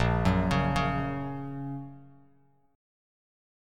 A#m#5 chord